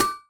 hammer.ogg